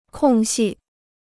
空隙 (kòng xì) Kostenloses Chinesisch-Wörterbuch